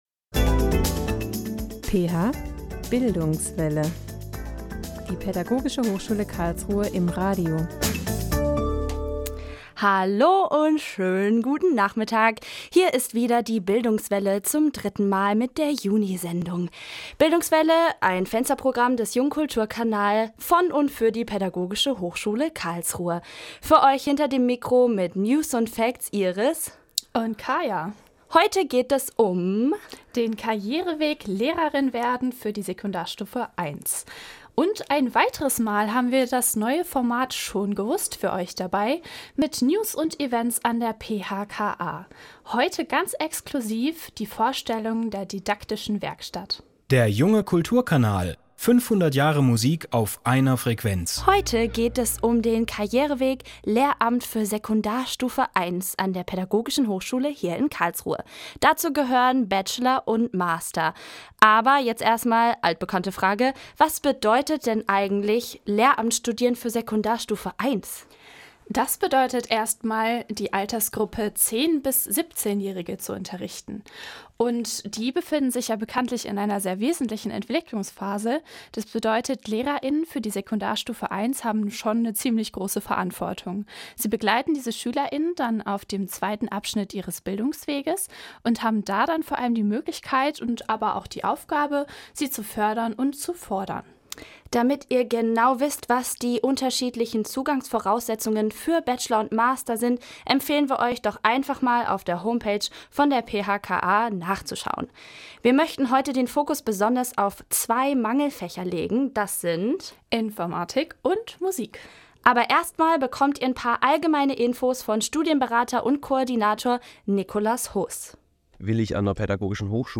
In der Juni-Sendung steht das Lehramtsstudium für Sekundarstufe 1 mit den Mangelfächern Informatik und Musik im Fokus. Dozent*innen und Studierende berichten aus dem Studium und stellen euch die beiden Fächer vor.